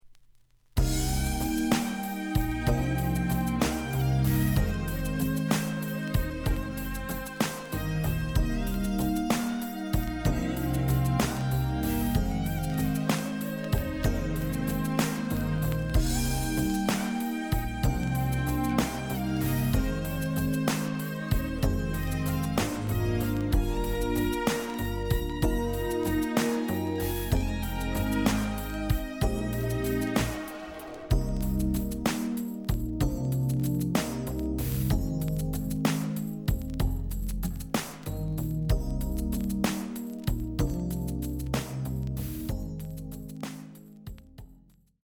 The audio sample is recorded from the actual item.
●Genre: Hip Hop / R&B
Looks good, but slight noise on both sides.)